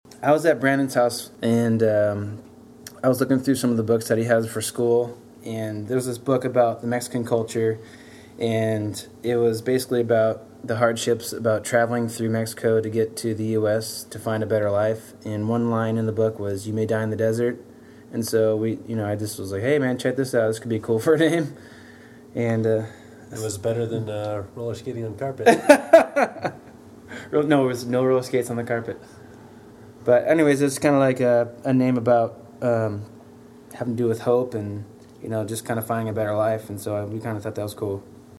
YOU MAY DIE IN THE DESERT INTERVIEW- October 2012